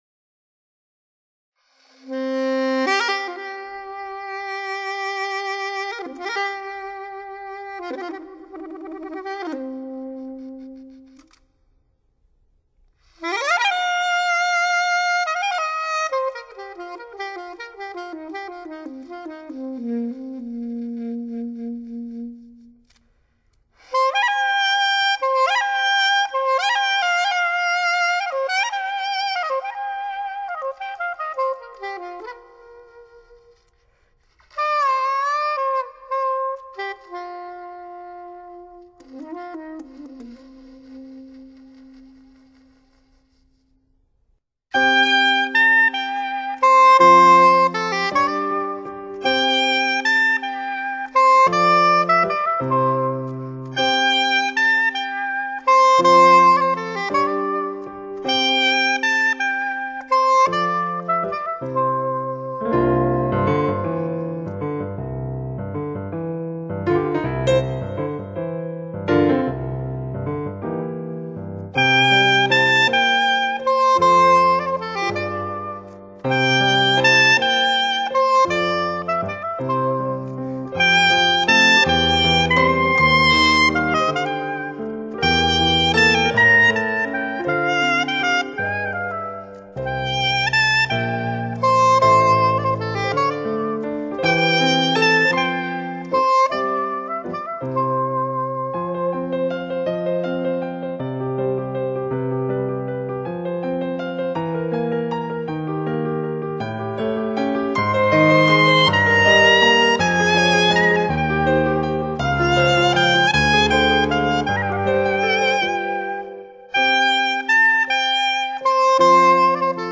这是一张清丽美妙的jazz fusion唱片，浓郁的lounge气息直教人陶醉。
是安静的萨克斯钢琴的二重奏
很优美，只有钢琴和萨斯管。